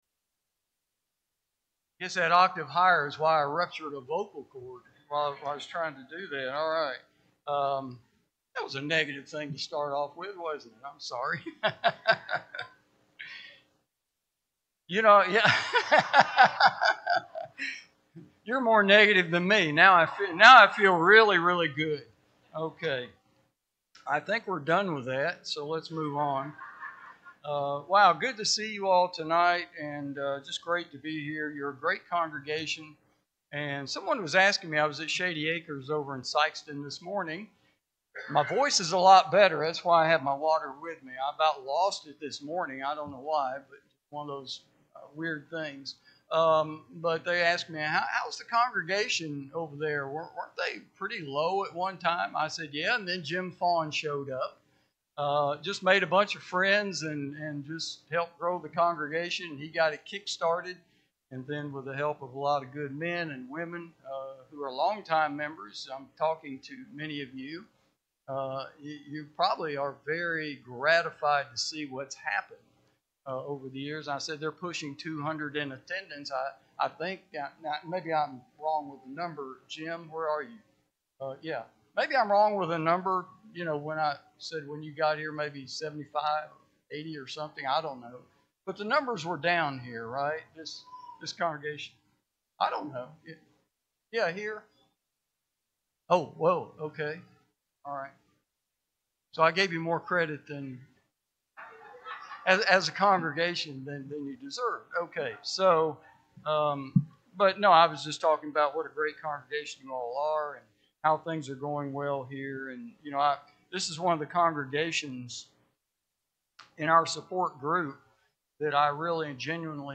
Sunday-PM-Sermon-2-15-26.mp3